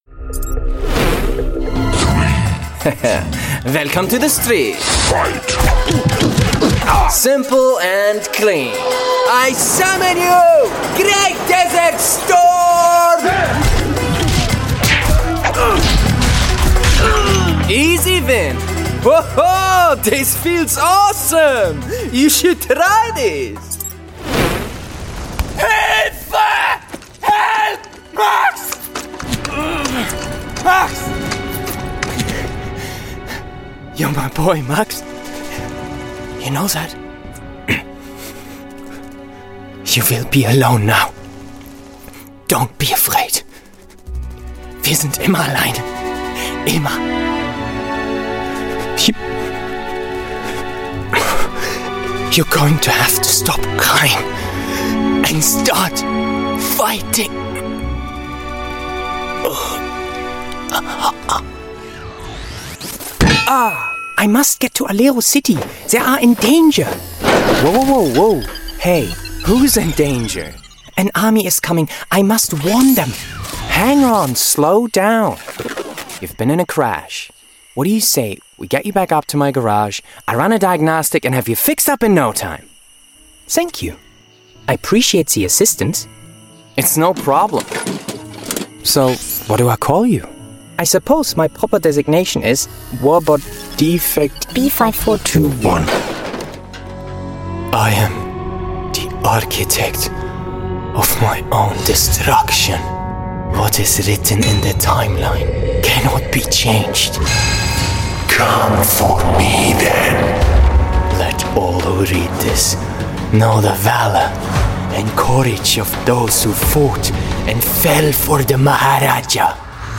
Showreel
Male / 20s, 30s / German Showreel https